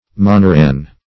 Moneran \Mo*ne"ran\, a. (Zool.)